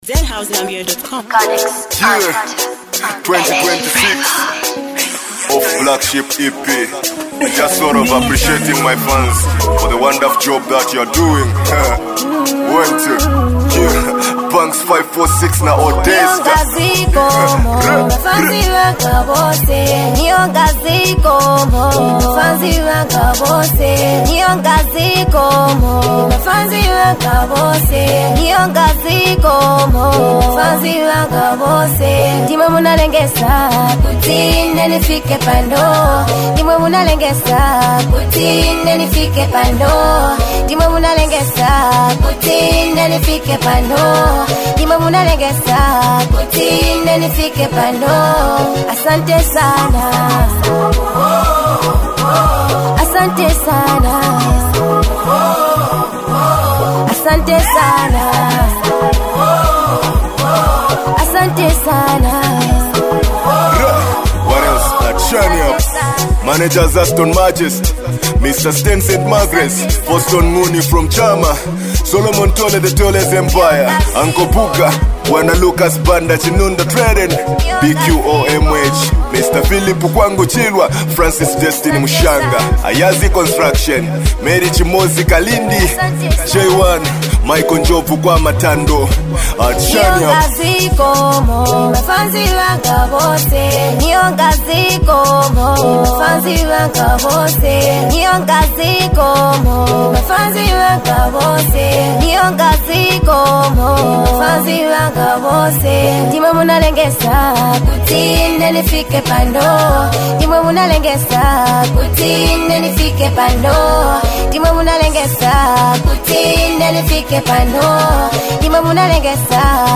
a heartfelt new single
With smooth vibes and gratitude-filled lyrics